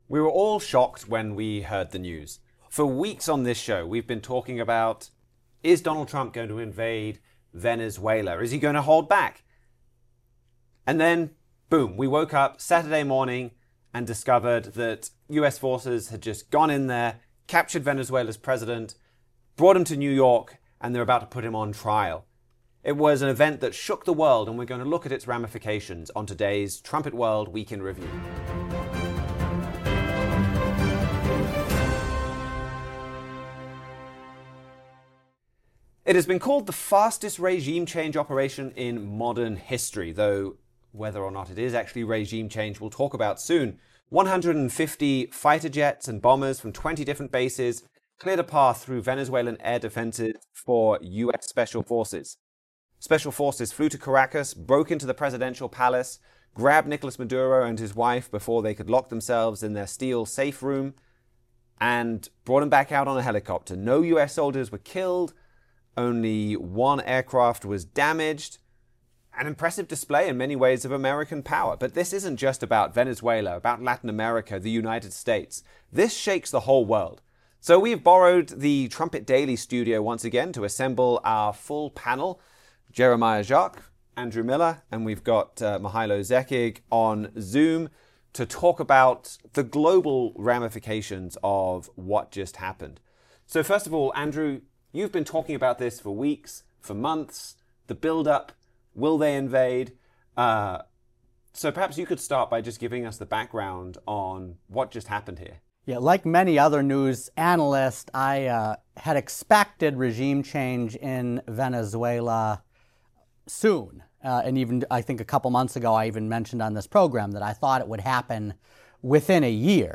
In this timely panel discussion